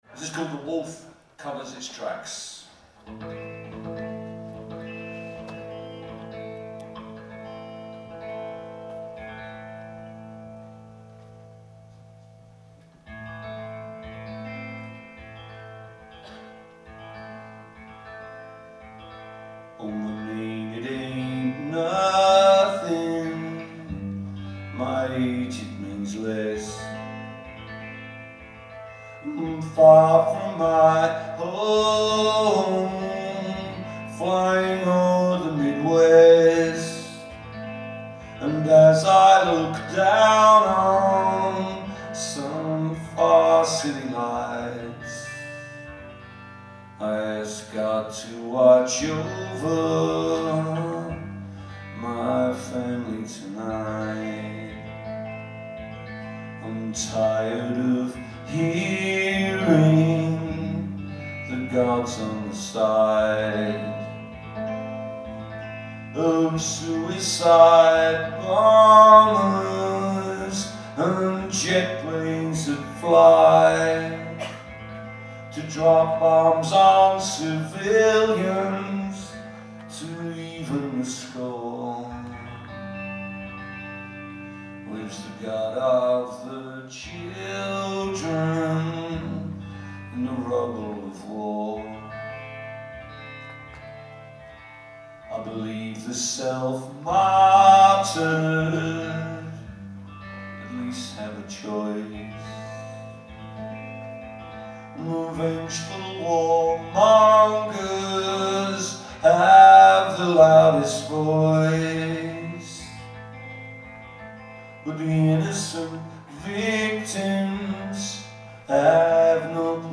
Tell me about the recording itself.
Live at the Somerville Theatre